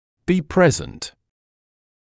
[biː ‘preznt][биː ‘прэзнт]присутствовать, иметься в наличии, иметься